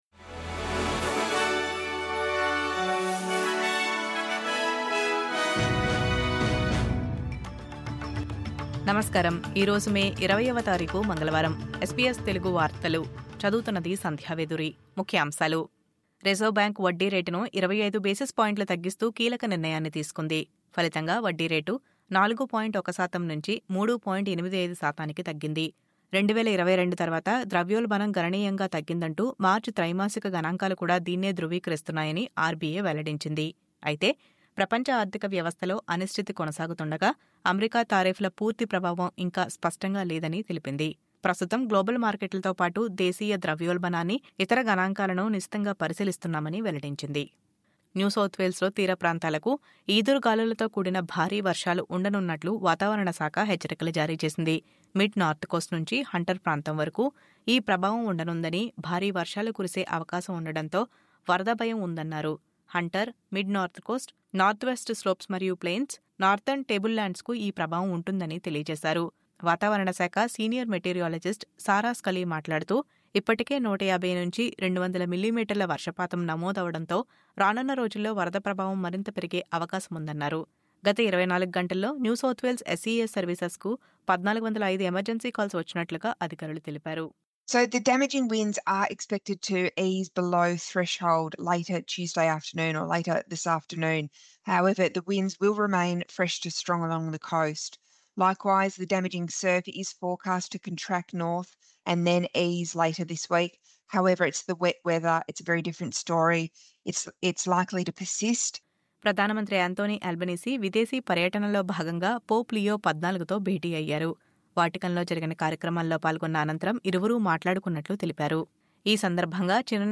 SBS తెలుగు వార్తలు..